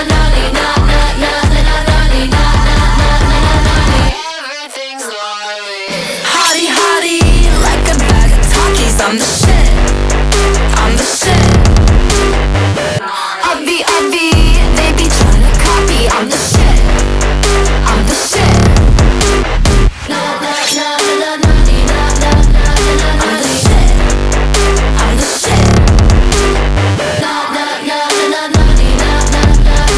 2025-04-30 Жанр: Поп музыка Длительность